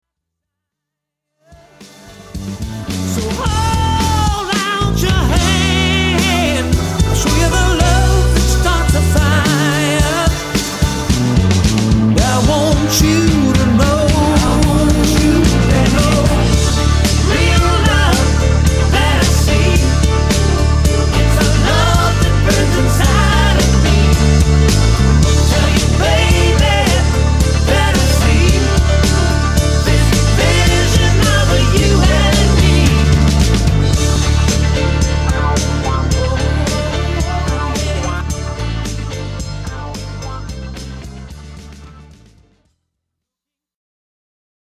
lead vocals and all guitars
drums on all tracks
bass on all tracks
keys, B-3 on all tracks
backing vocals
all strings